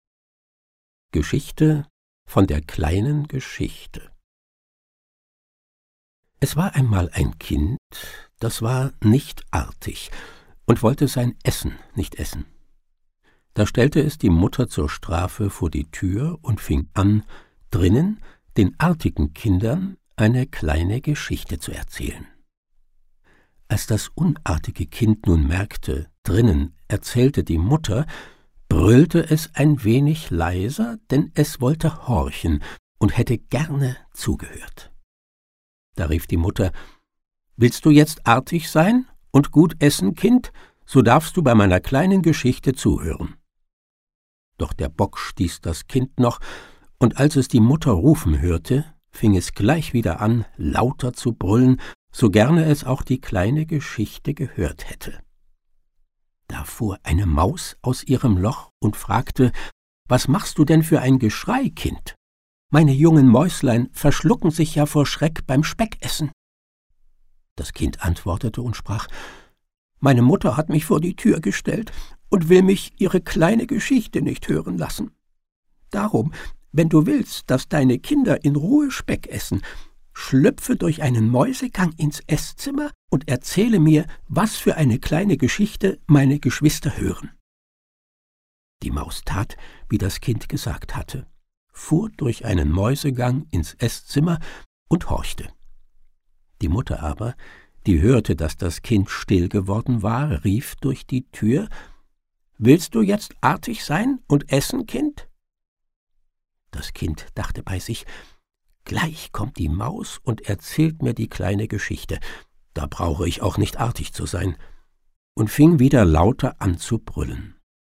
Schlagworte Gute-Nacht-Geschichten • Hörbuch • Märchen